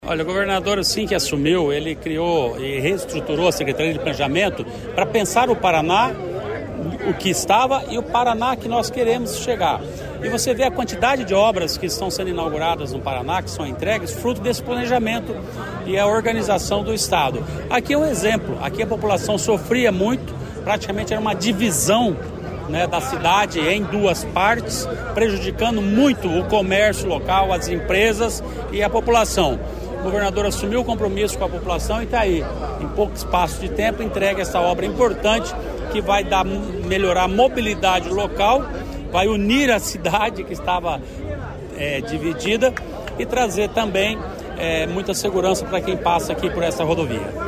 Sonora do secretário Estadual do Planejamento, Ulisses Maia, sobre a inauguração dos viadutos em Sarandi sobre a BR-376